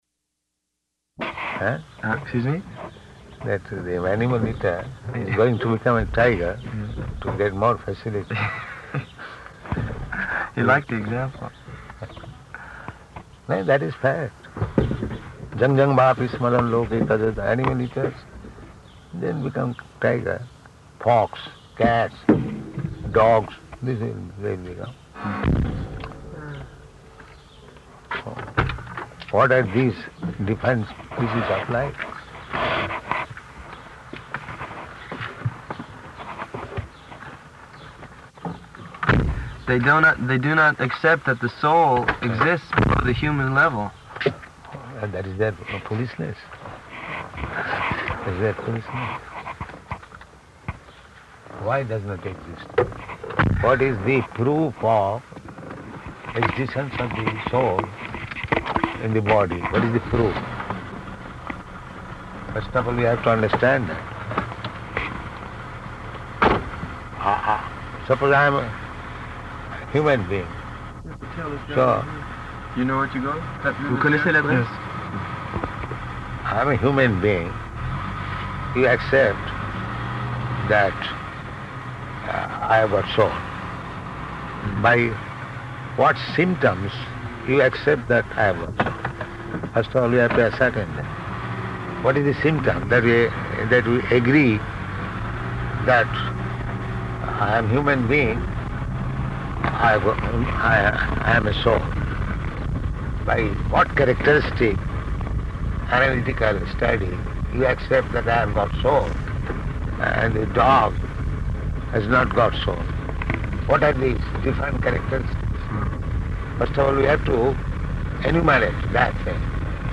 Car Conversation after meeting with Cardinal Danielou
Car Conversation after meeting with Cardinal Danielou --:-- --:-- Type: Conversation Dated: August 9th 1973 Location: Paris Audio file: 730809CR.PAR.mp3 Prabhupāda: Eh?